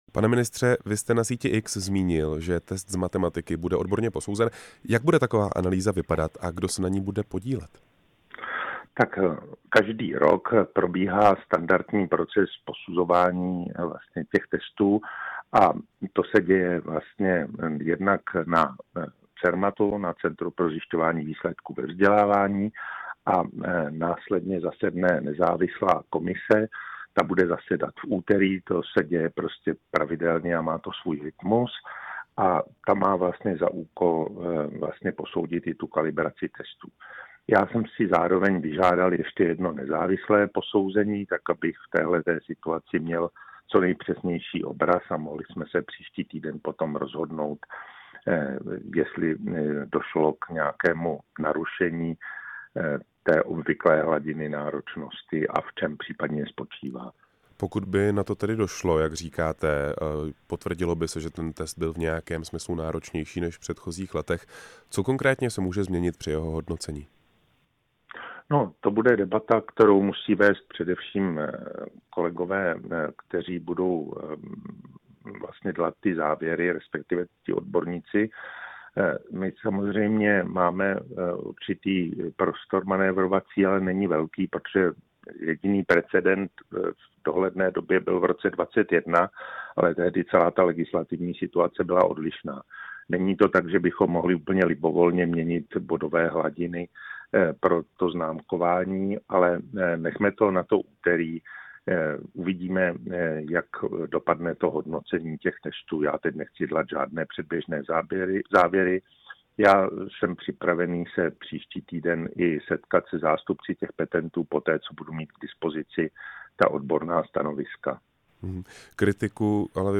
Ministr školství Mikuláš Bek slíbil odborné posouzení a případné úpravy hodnocení. Zároveň čelí kritice opozice kvůli návrhu reformy financování nepedagogických pracovníků ve školství. Ministra Mikuláše Beka jsme přivítali ve vysílání Rádia Prostor.
Rozhovor s ministrem školství Mikulášem Bekem